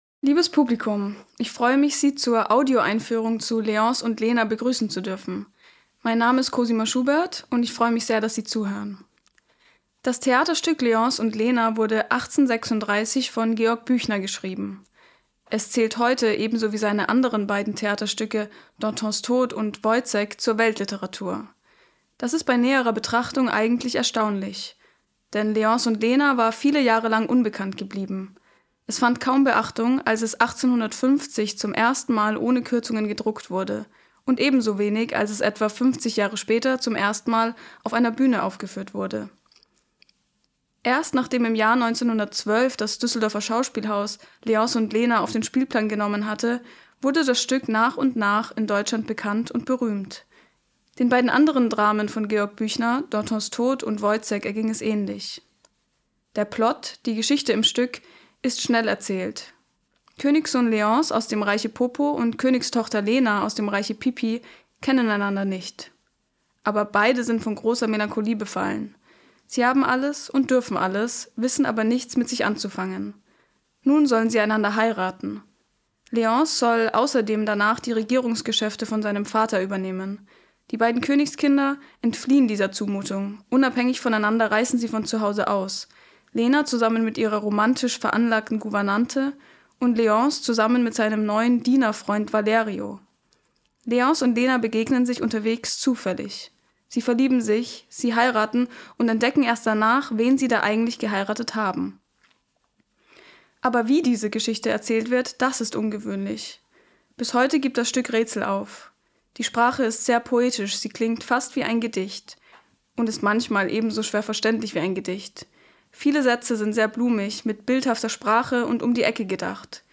tdo_Audioeinfuehrung_Leonce.mp3